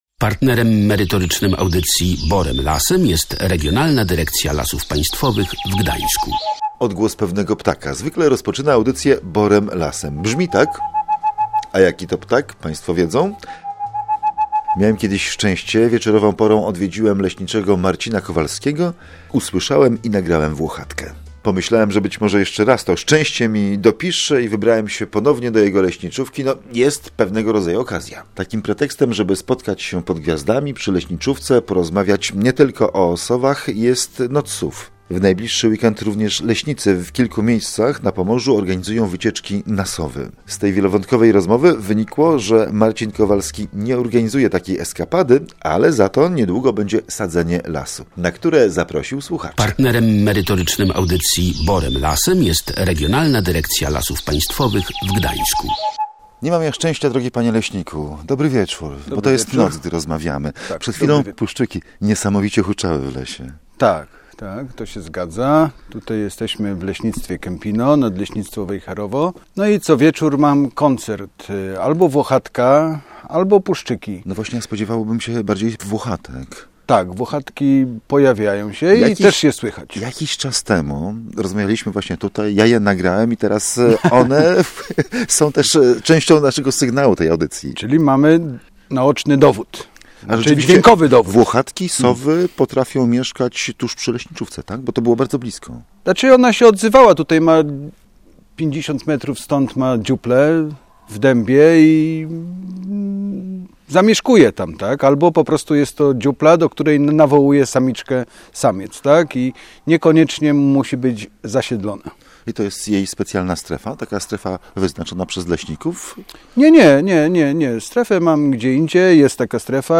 O sowach, dalekich rowerowych eskapadach i lesie – rozmowa